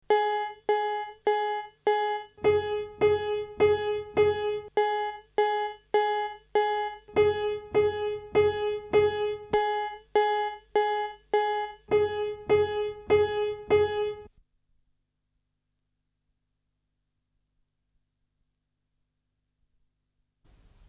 Il s'agit probablement du fait qu'une composante caractéristique de percussion («le coup») au début de la note joue un rôle décisif dans le caractère du son du piano.
L'importance du son lié au «coup» est illustrée dans l'exemple de son n°8 -
L'auditeur sera sans doute d'accord que le son de piano normal enregistré dans la salle a une certaine ressemblance avec les sons de l’atelier d'un forgeron. D’autre part il manque au son isolé de la corde quelque chose du caractère intéressant du piano, il ressemble plus au son d’une corde pincée qu’à celui d’une corde frappée.